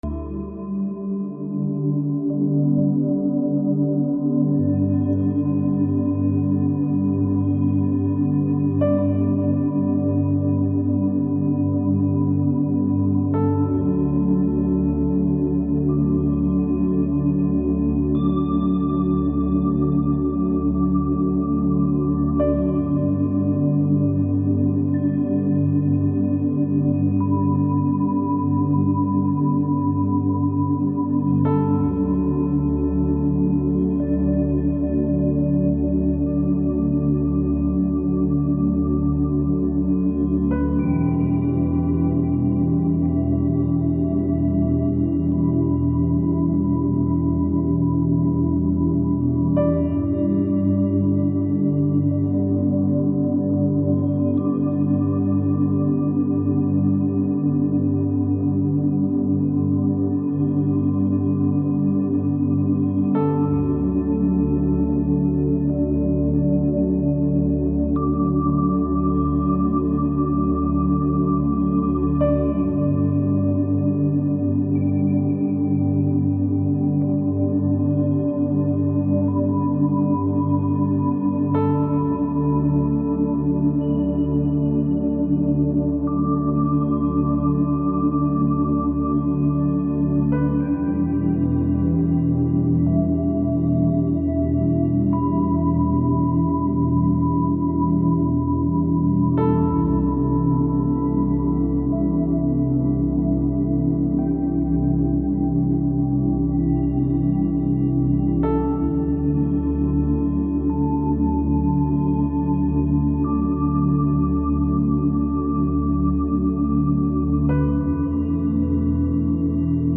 La fréquence 285hz active la guérison des blessures,
La-frequence-285hz-active-la-guerison-des-blessures.mp3